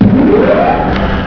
TUER.WAV